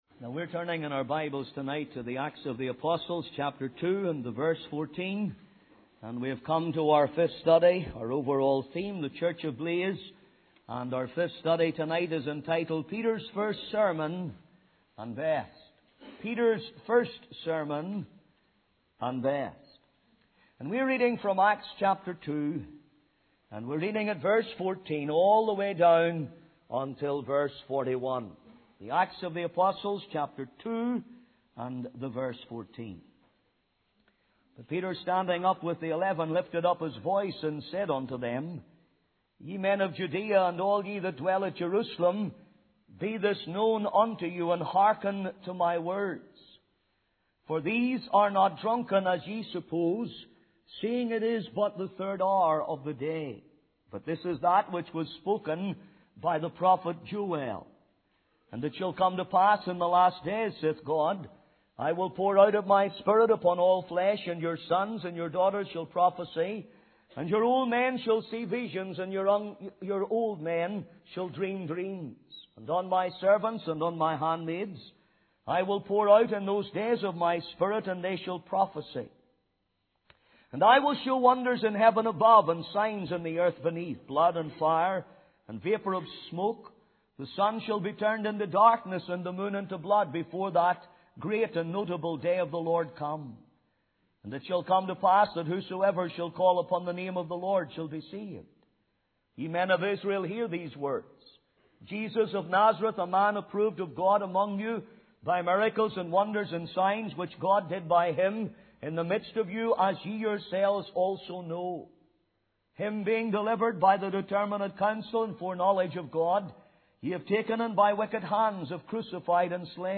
Peter's First Sermon - Bring the Book
05.-Peters-First-Sermon.mp3